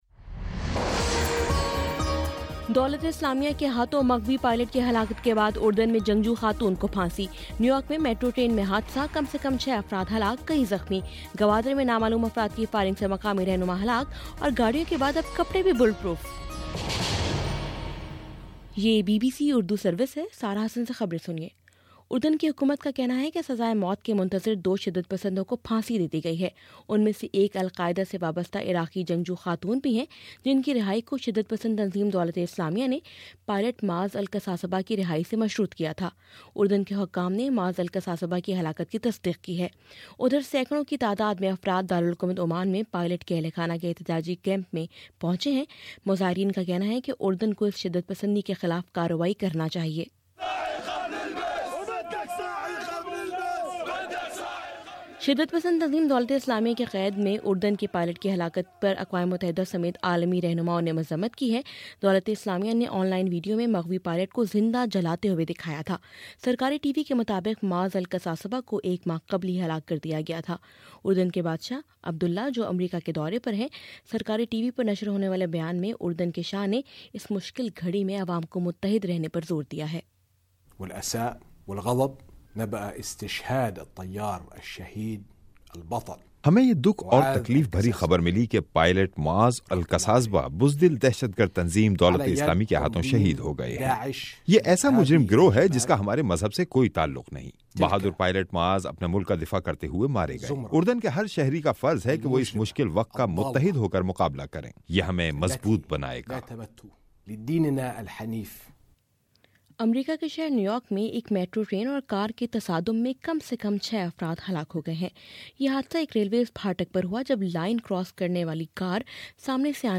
فروری 04: صبح نو بجے کا نیوز بُلیٹن